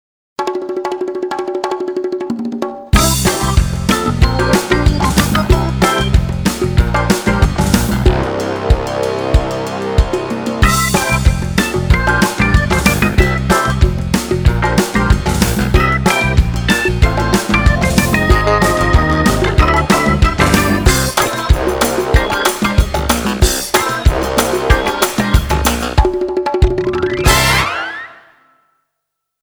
ist showy, groovy und easy. Nennt man kurz: Retro.
Titelmusik